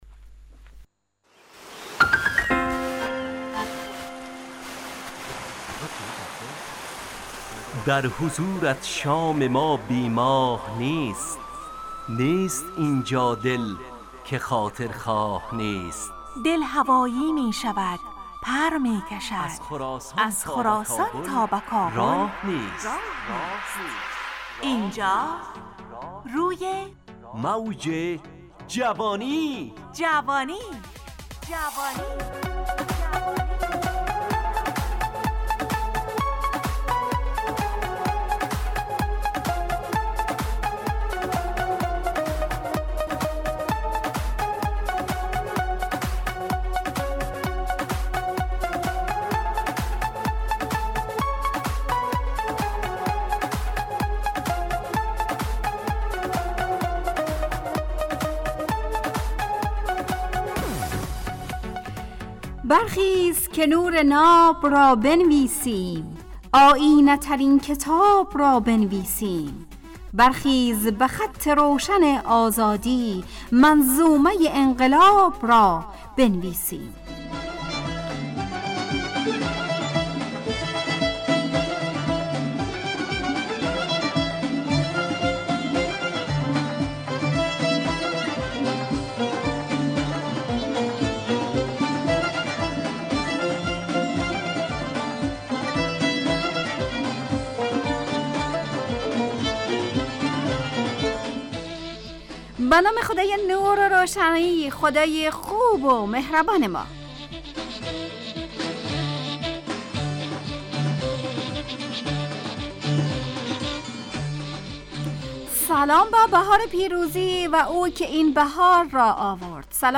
همراه با ترانه و موسیقی مدت برنامه 55 دقیقه . بحث محوری این هفته (هنر) تهیه کننده